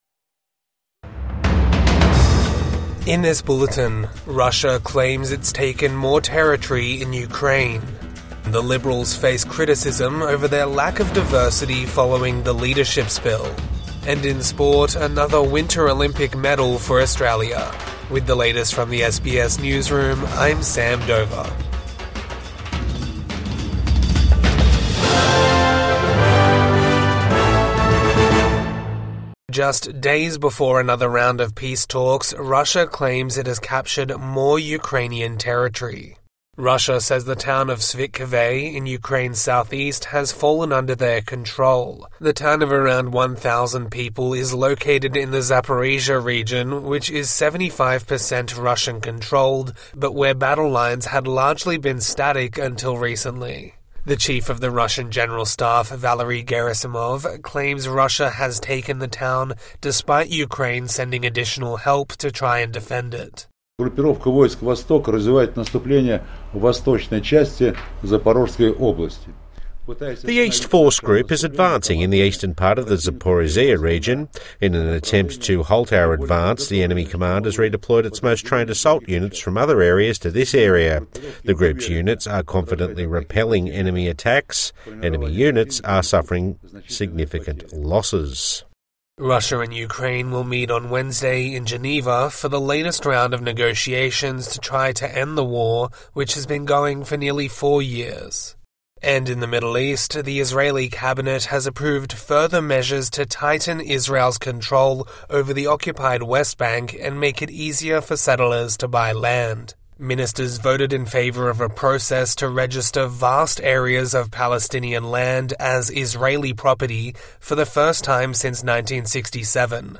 Russia claims more territory in Ukraine | Morning News Bulletin 16 February 2026